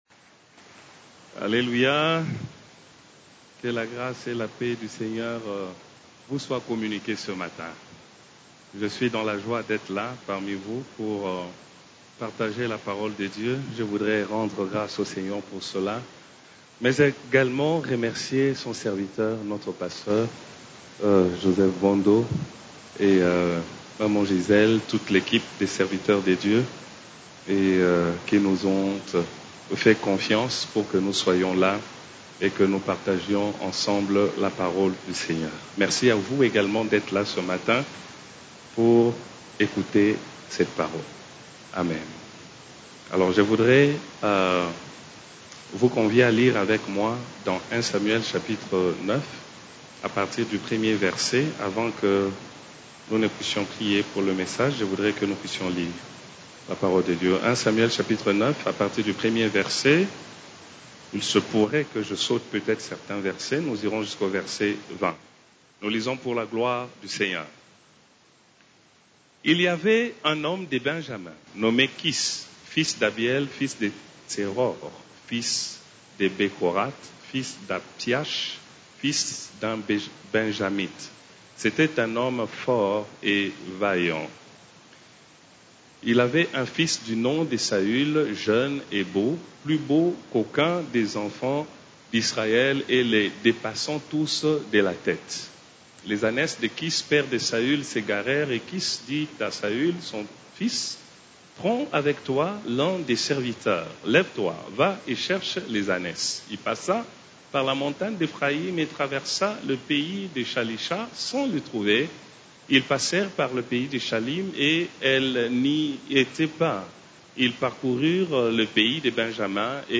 CEF la Borne, Culte du Dimanche, Qu'as-tu perdu ?